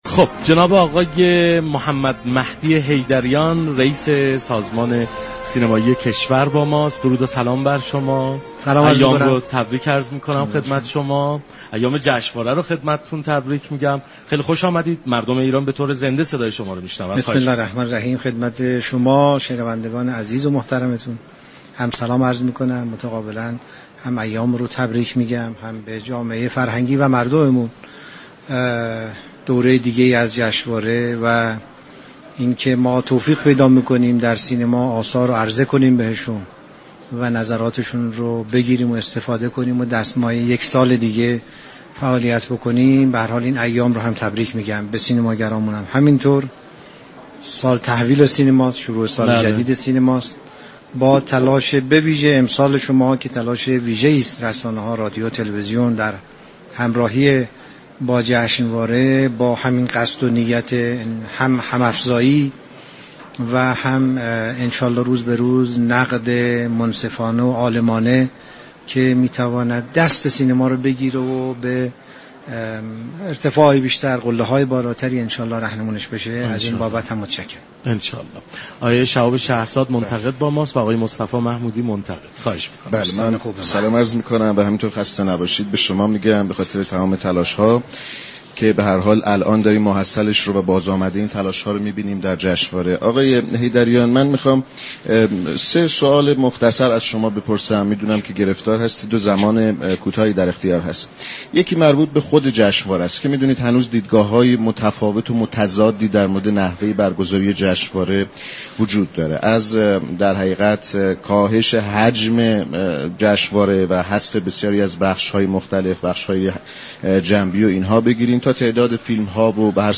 رییس سازمان سینمایی ایران در گفت و گو با كافه هنر رادیو ایران گفت: برنامه جامع و سیستماتیك اگر اجرایی شود؛ قطعا هر گونه تغییر به نفع سینمای ایران تمام می گردد.